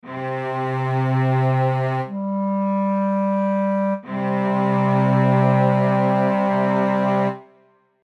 Now divide by 2 and you have 3/2, one and a half times the original frequency, and just a fifth above.